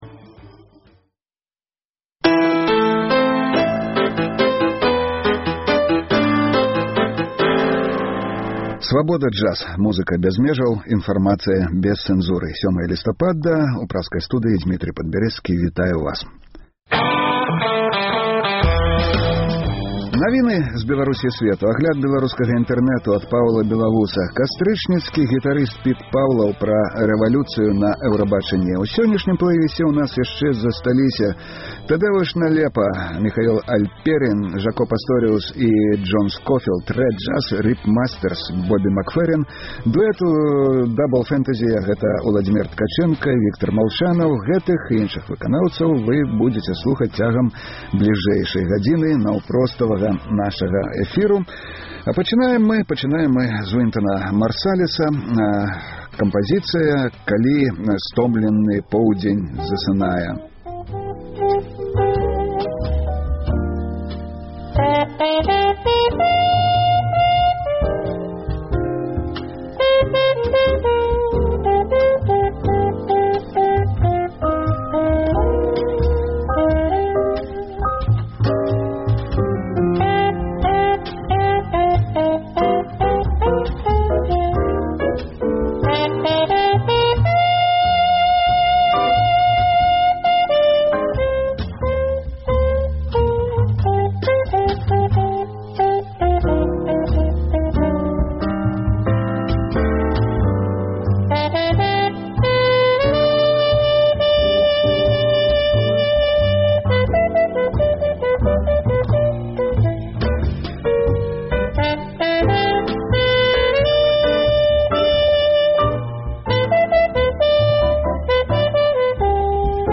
Слухайце ад 12:00 да 14:00 жывы эфір "Свабоды"!